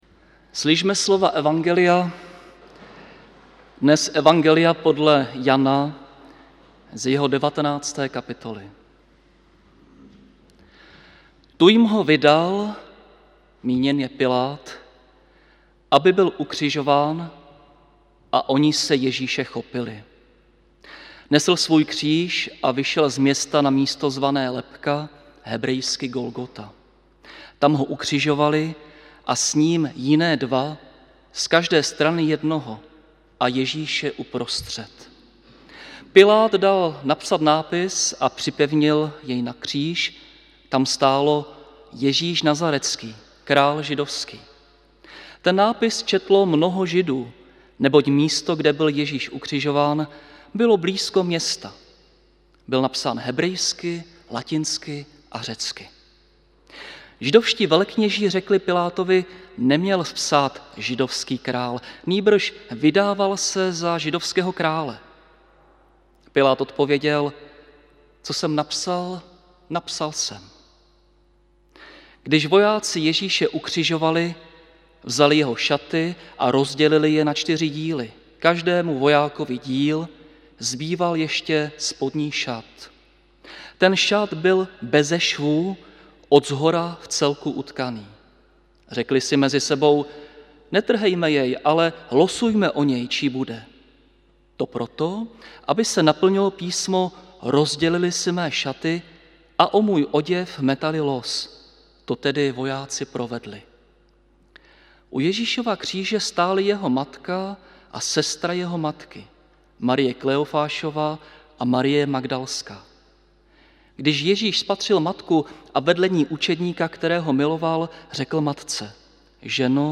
Velký pátek – bohoslužby se slavením sv. Večeře Páně – 15. dubna 2022 AD
Kázání